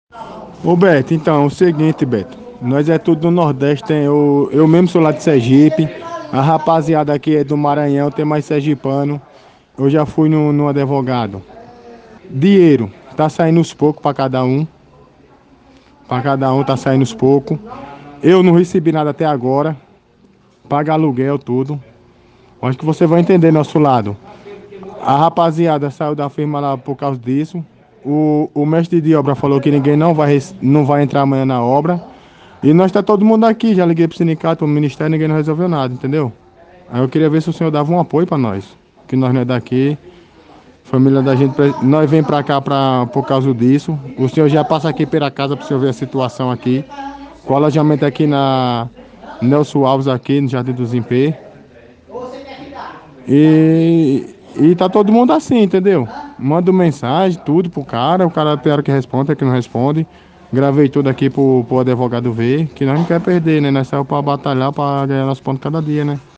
Um deles falou das dificuldades com a nossa reportagem: